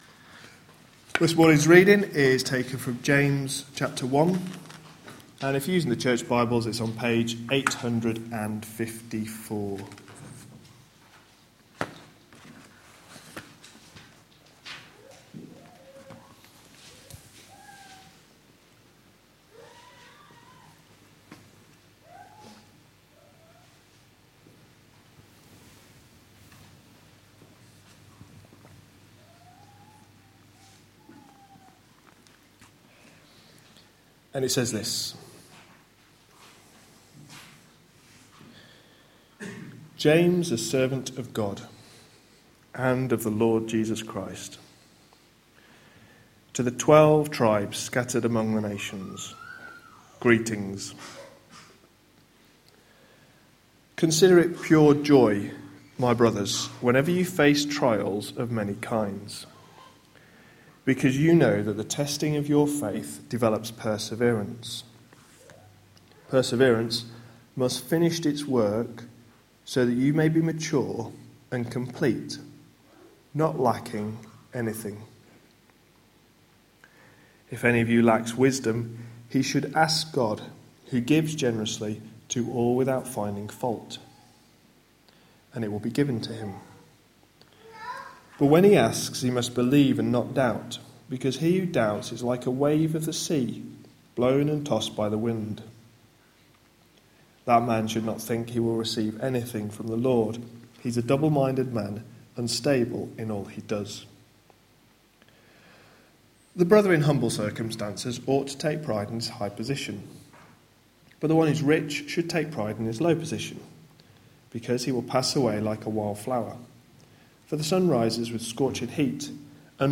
A sermon preached on 3rd May, 2015, as part of our James series.